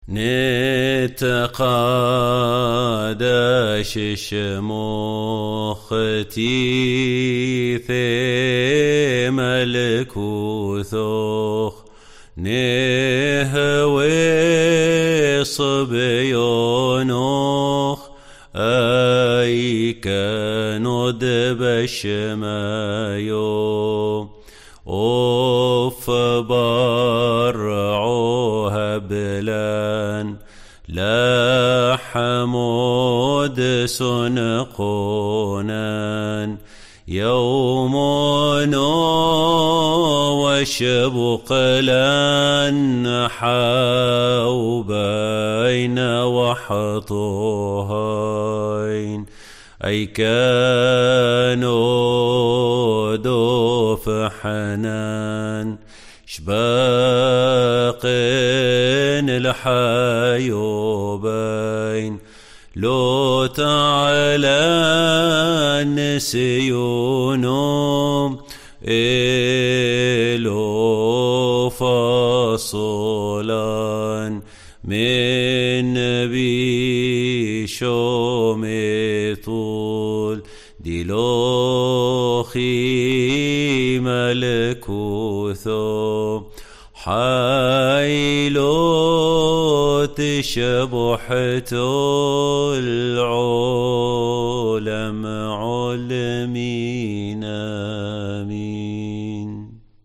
Mons. Nicolaos Matti Abd Alahad, Arzobispo de la Iglesia Sirio Ortodoxa de Antioquía, ha estado en COPE para rezar el Padre Nuestro en arameo